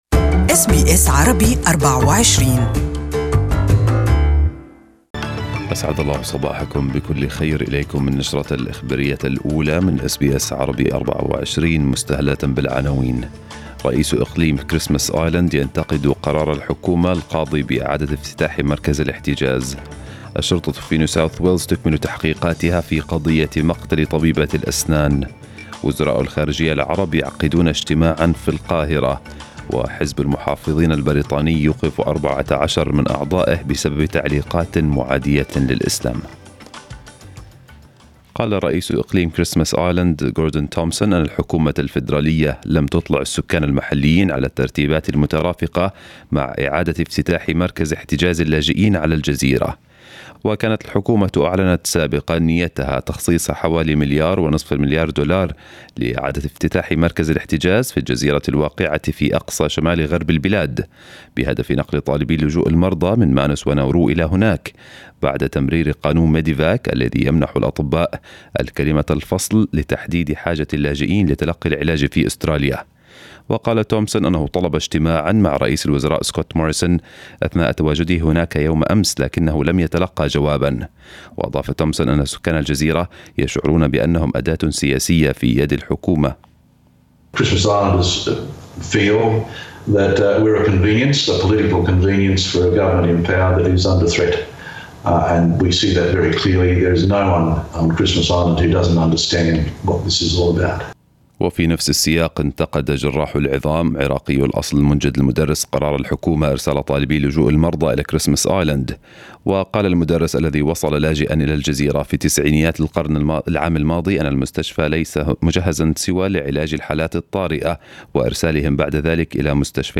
News bulletin in Arabic for the morning